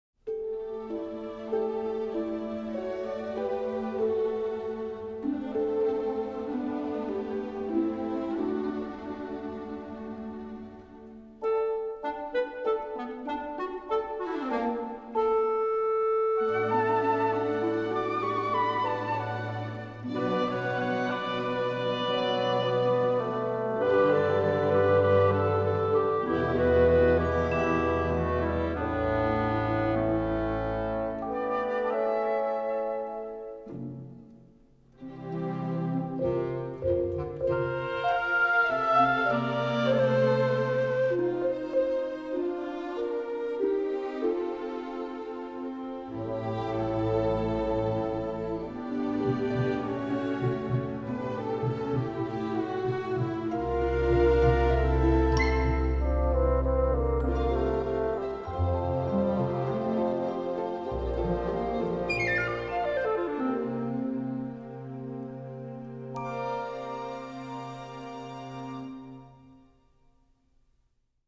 На этой странице вы найдете саундтрек к мультфильму \